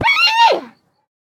assets / minecraft / sounds / mob / panda / death4.ogg